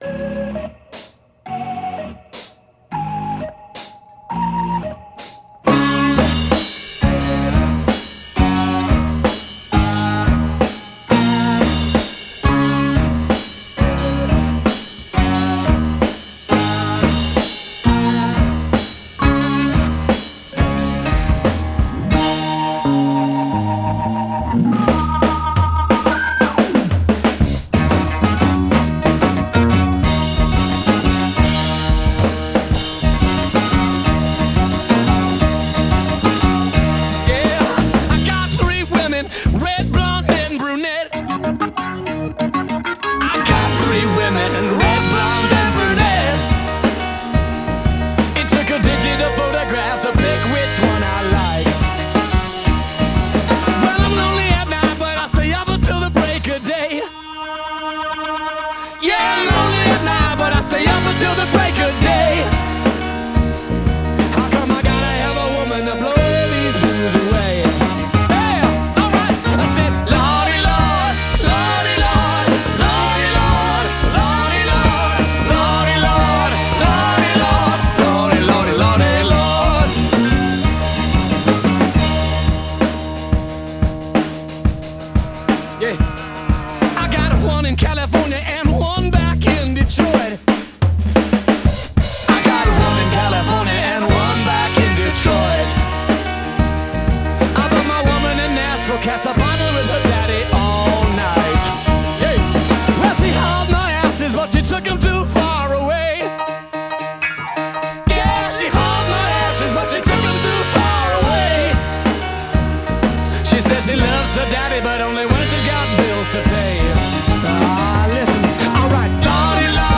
Genre: Blues Rock